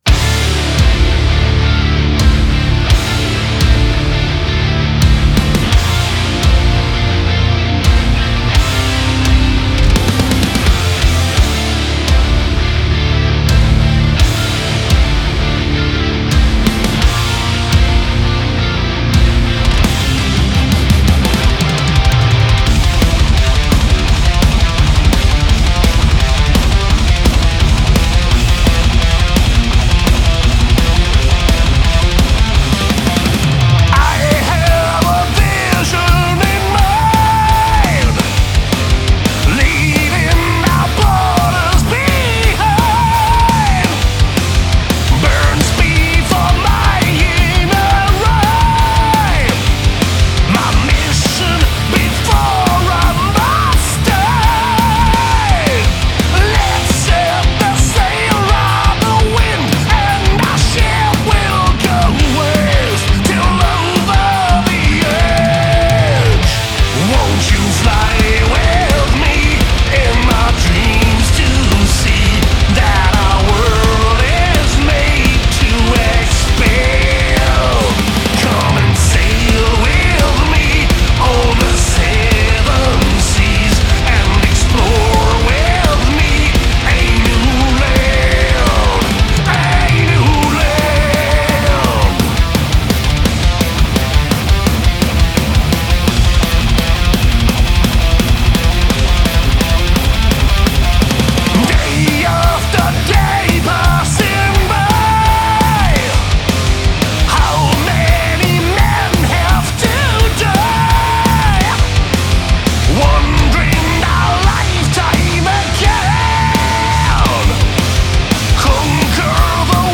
Genre: Power Metal, Heavy metal Trash Metal https